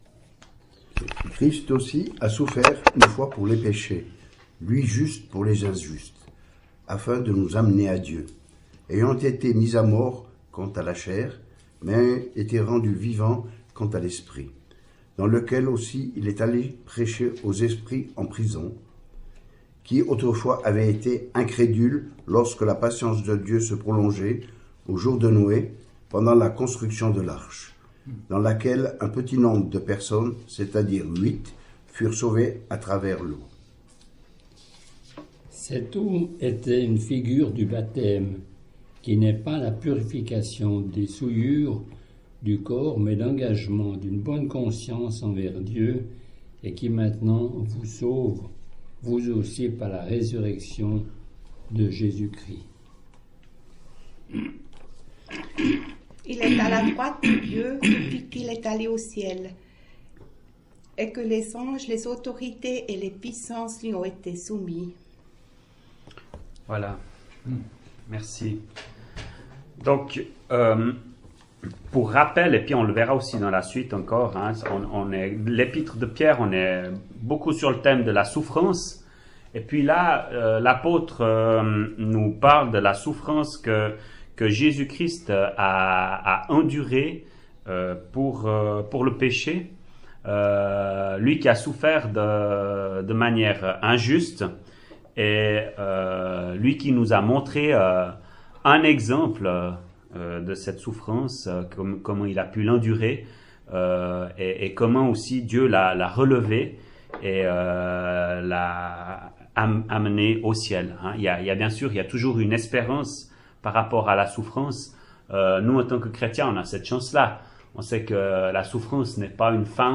[Chapelle de l’Espoir] - Étude biblique : Première Épître de Pierre, 8ème partie
ÉTUDE BIBLIQUE : Evole, le 31.01.2018